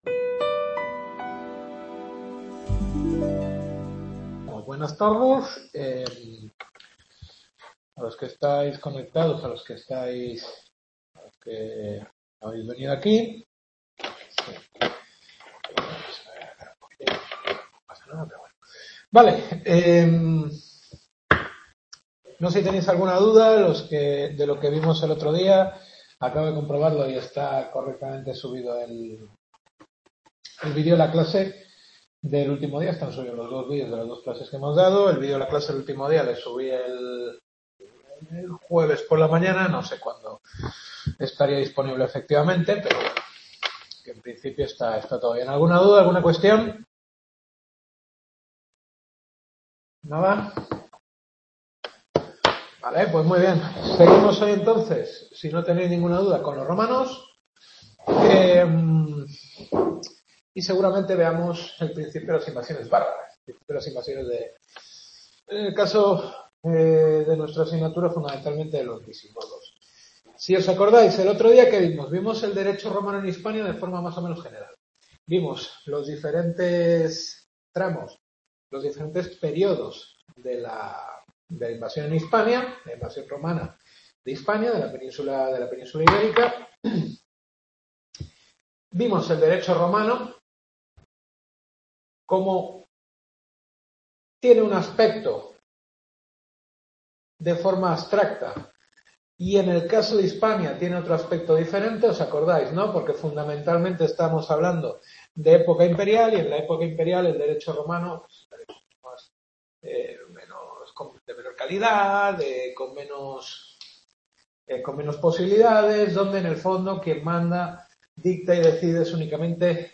Tercera clase.